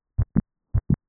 Gespaltener 2-ter Herzton: Mitralklppenprolaps
Dog-SplitS2.wav